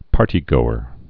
(pärtē-gōər)